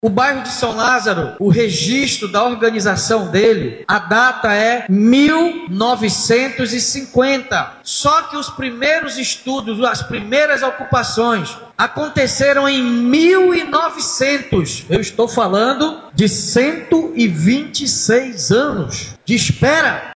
Durante a cerimônia, o prefeito David Almeida destacou o caráter histórico da entrega e ressaltou que o bairro possui organização oficial desde 1950.